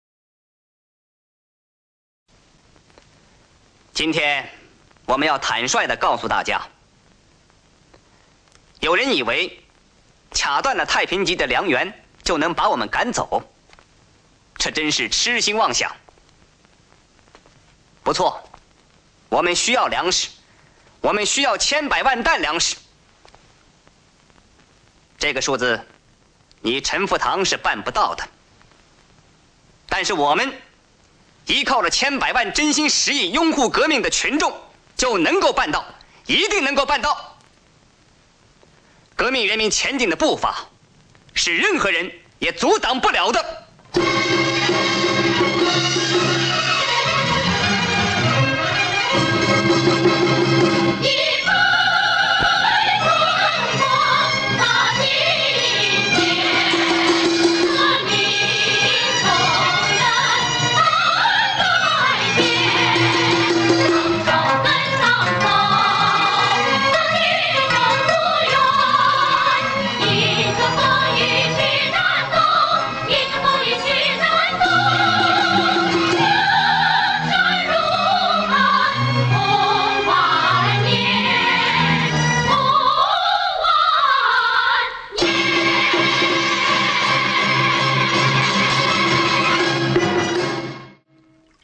教育私商和歌曲